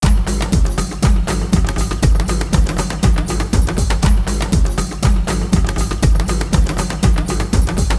tribaldrums.wav